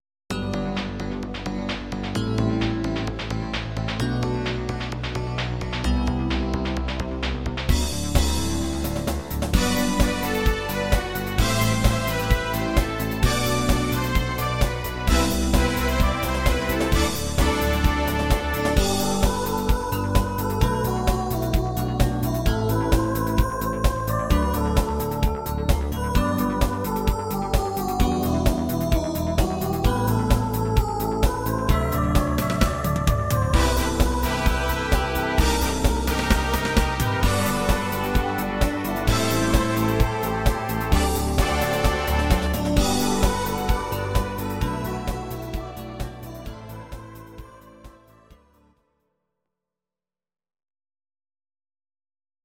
These are MP3 versions of our MIDI file catalogue.
Please note: no vocals and no karaoke included.
dance mix